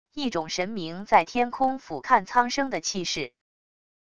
一种神明在天空俯瞰苍生的气势wav音频